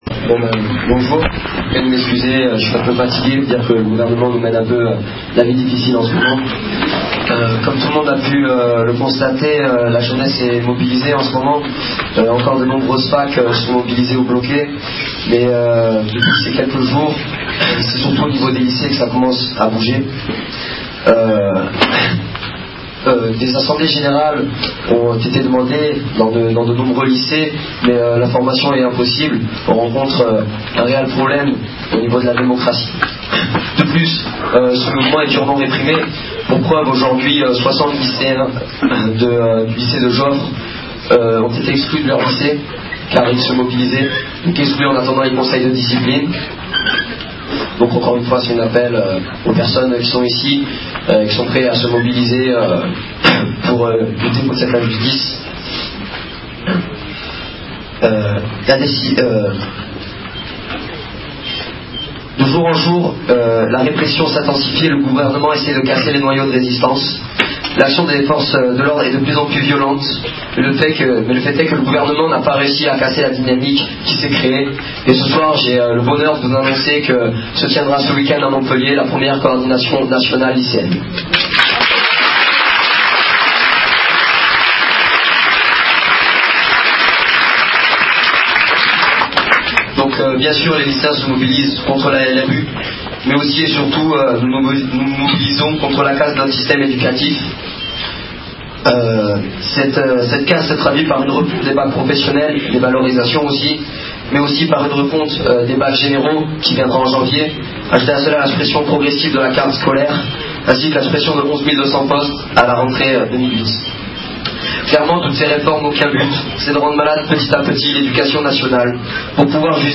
Enregistrement des interventions
Rencontre du 6 décembre 2007 à Montpellier
Intervention en préambule, des étudiants en lutte